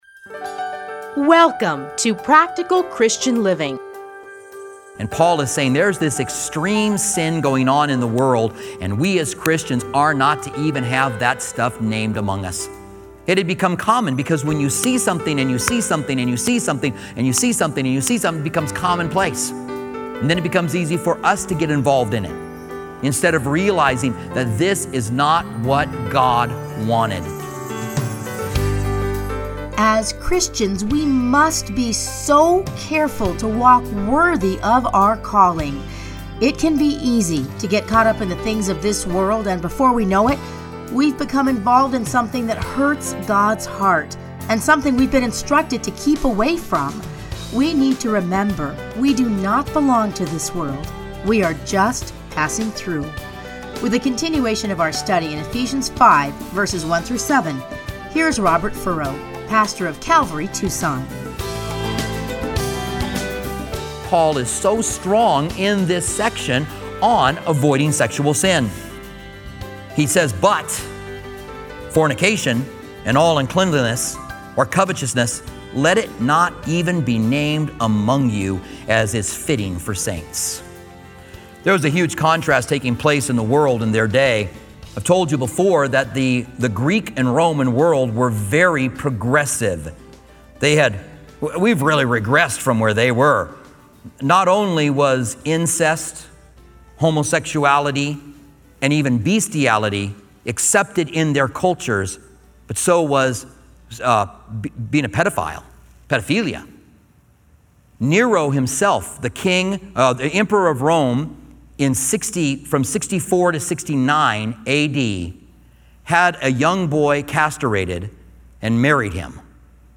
Playlists Commentary on Ephesians Download Audio